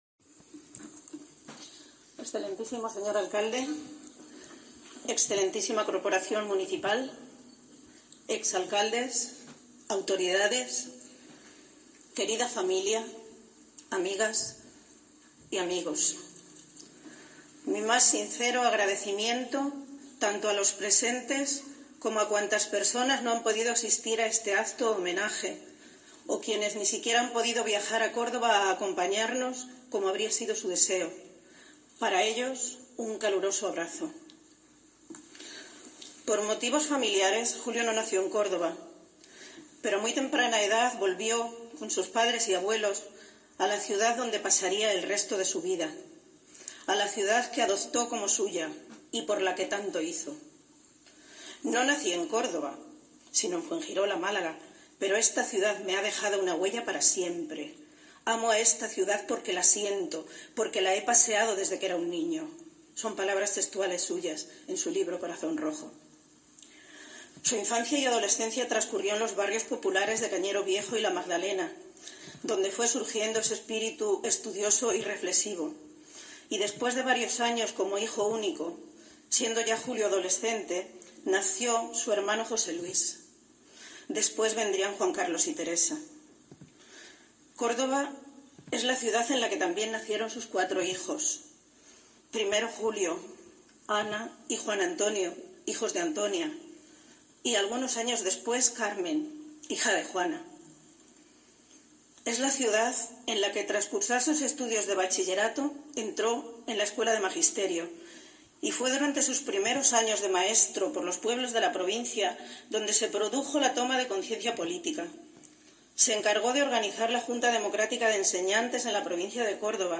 Representantes institucionales, políticos de todos los partidos y miembros de colectivos sociales arroparon a la familia durante la ceremonia